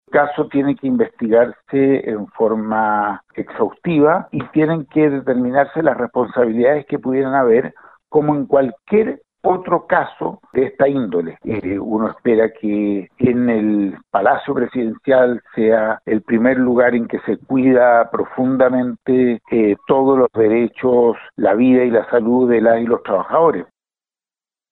El diputado de Acción Humanista, Tomás Hirsch, también se refirió al caso, subrayando la necesidad de realizar las investigaciones pertinentes para esclarecer los hechos.